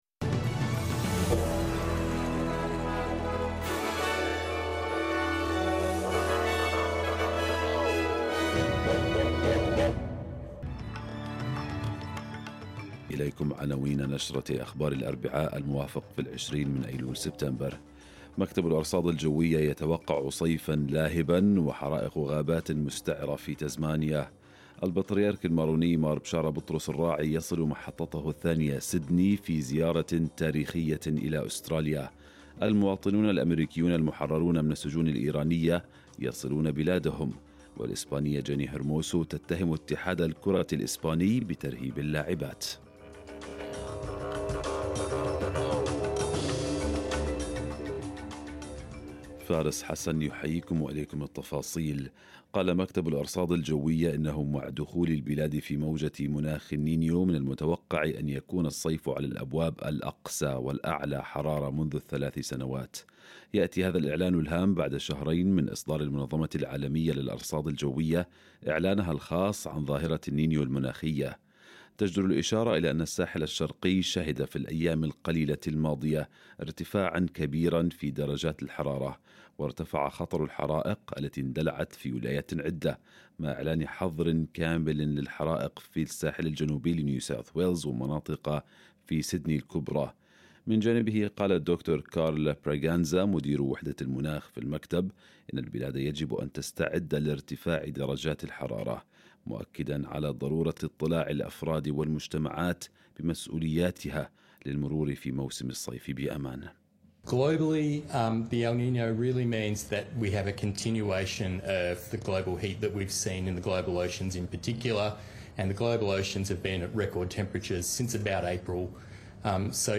نشرة أخبار الصباح 20/9/2023